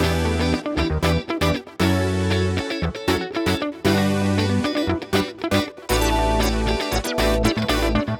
11 Backing PT4.wav